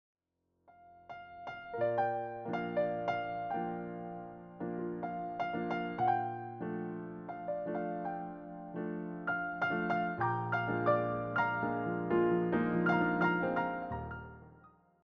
clear and expressive piano arrangements